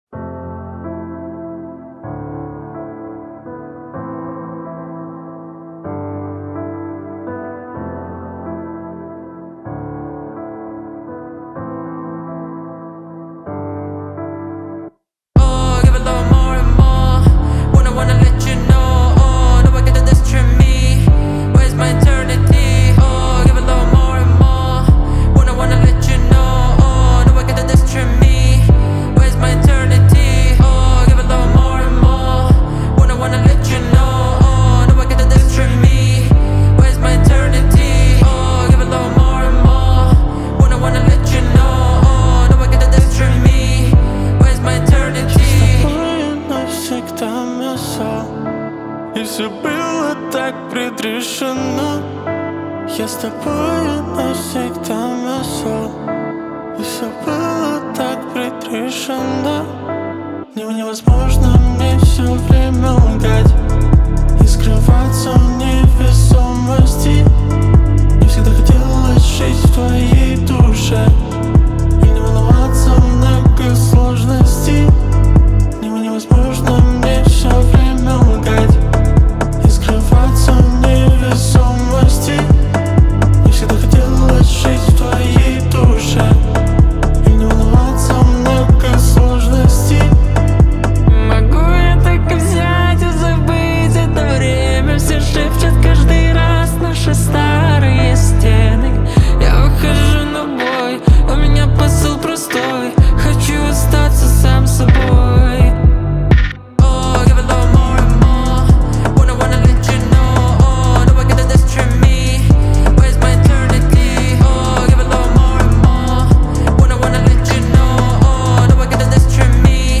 ترکیب منحصر بفرد پاپ و R&B